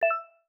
Message Ping X2.wav